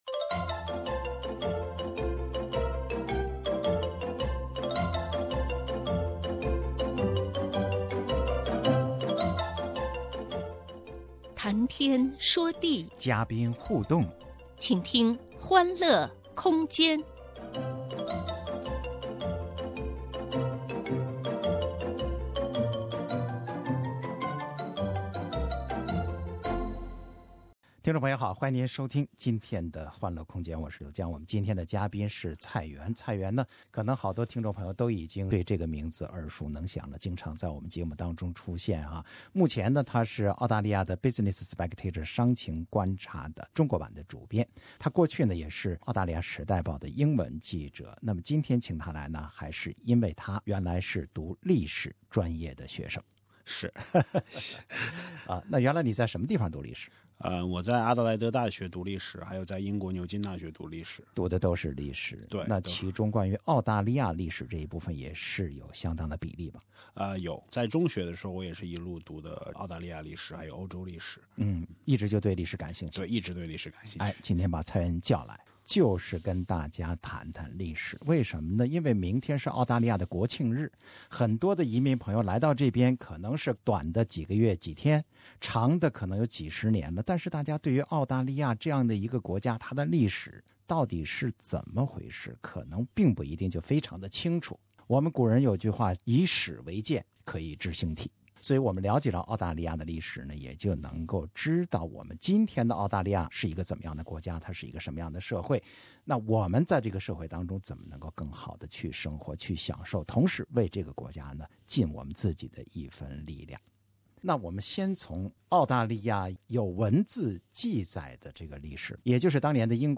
欢乐空间：澳大利亚国庆日特别节目-澳洲历史故事